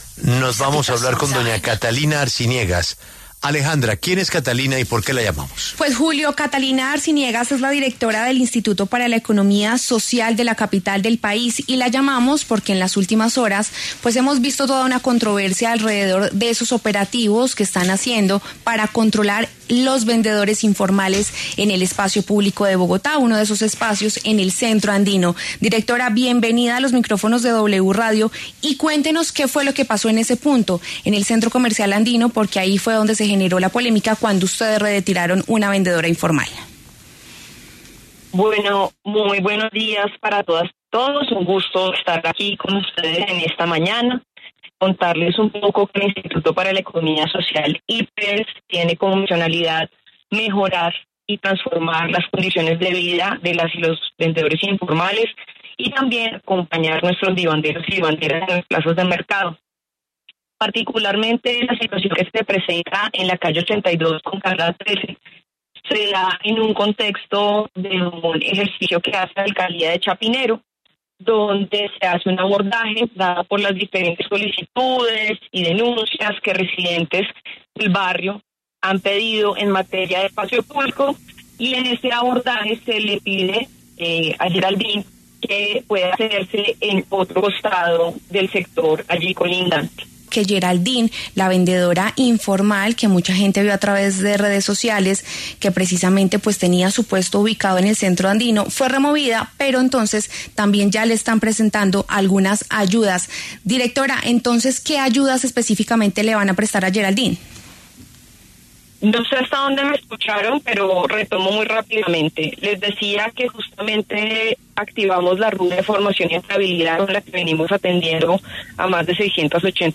En los micrófonos de La W, la directora del Instituto para la Economía Social, IPES, Catalina Arciniegas, se refirió al polémico operativo donde una mujer, vendedora informal, es retirada de la zona T, en el norte de la capital.